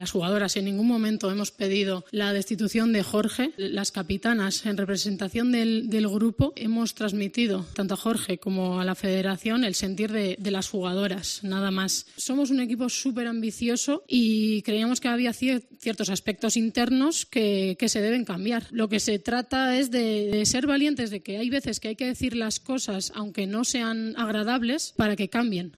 "Quería aclarar que hoy estamos aquí porque lo hemos pedido, nadie nos ha obligado. Por toda la información que ha salido estos días, queríamos dar nuestra versión de la situación, que no está siendo nada fácil", dijo en rueda de prensa este jueves en La Ciudad del Fútbol de Las Rozas (Madrid).